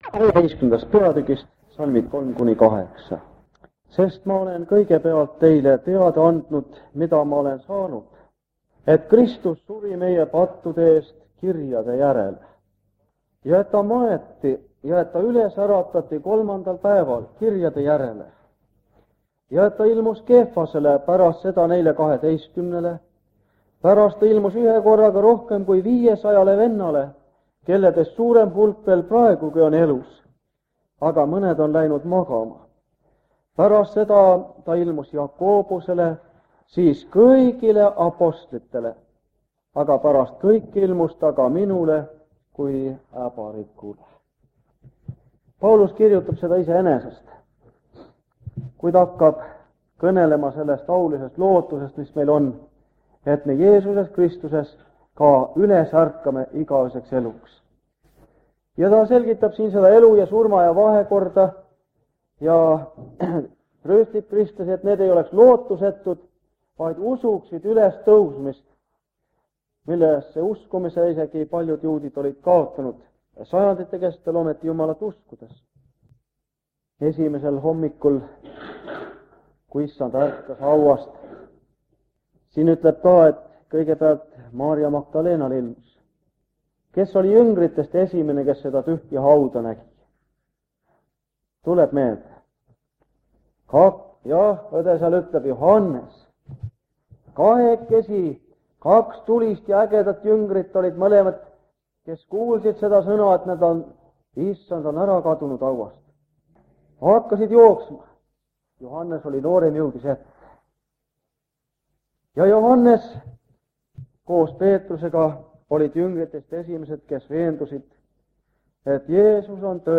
Jutlused
Ilmutuse raamatu seeriakoosolekud Kingissepa linna adventkoguduses